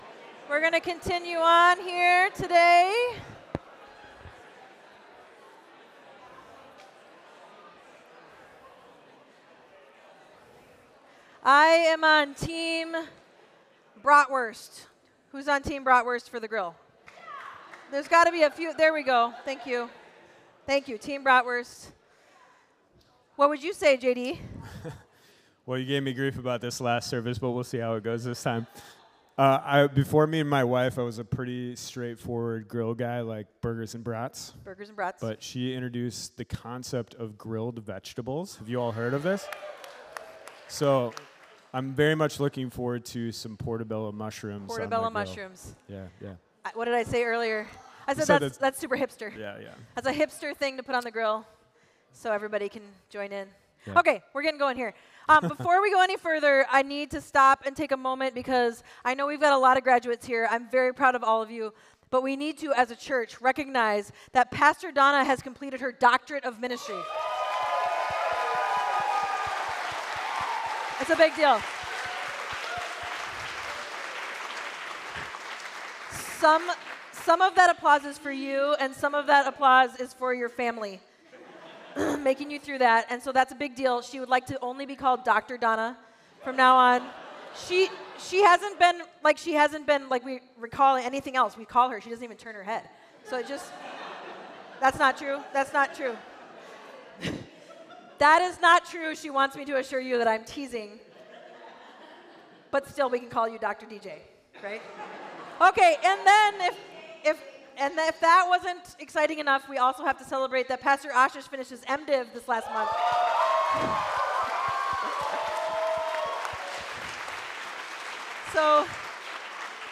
Preached
Mill City Church Sermons Love Your Neighbor: Move Towards Your Neighbor Jun 04 2023 | 00:38:16 1x 00:00 / 00:38:16 Subscribe Share RSS Feed Share Link Embed